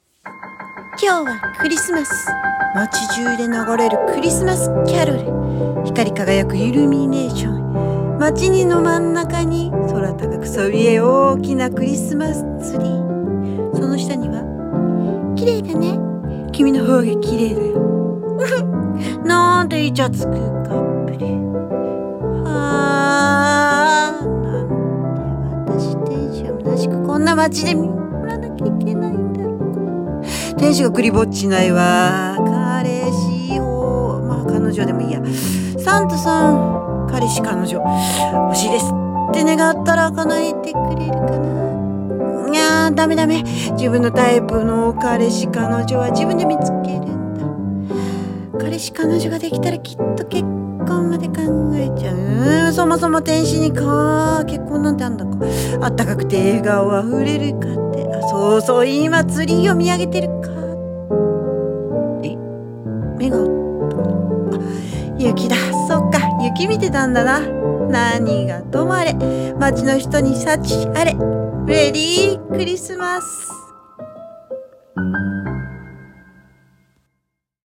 さんの投稿した曲一覧 を表示 【1人声劇】クリスマスの天使のひとりごと / コラボ名前：。